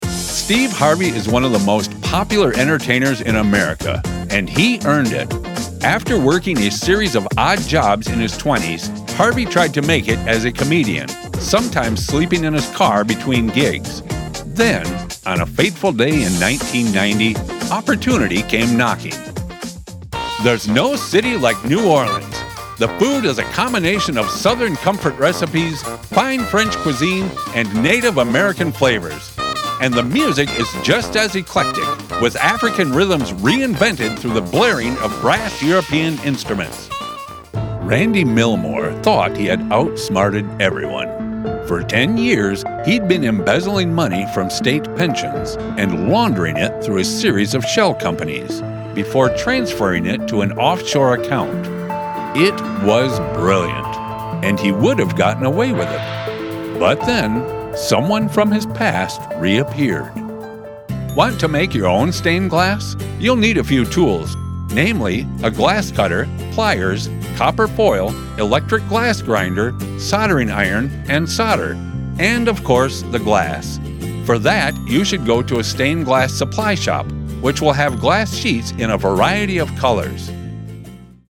Commercial Demo
Midwest, General American
Middle Aged